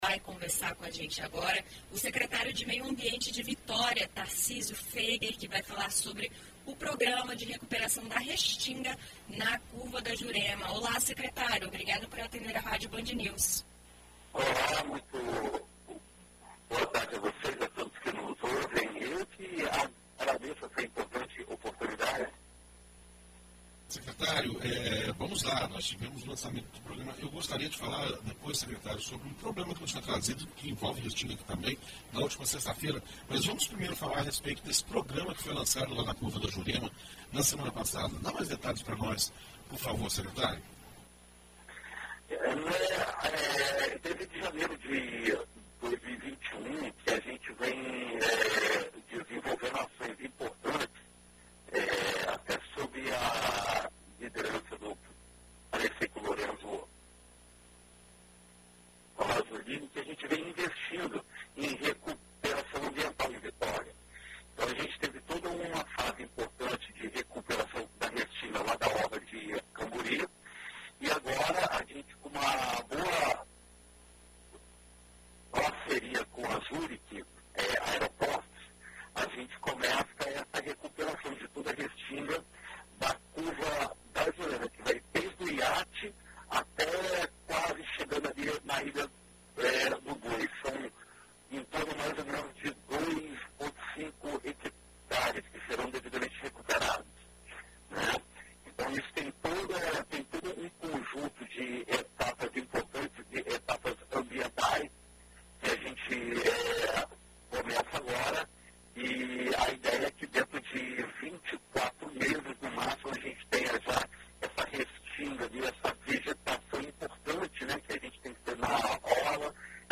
Em entrevista à BandNews FM ES nesta segunda-feira (27), o secretário de Meio Ambiente de Vitória, Tarcísio Föeger, fala sobre o programa.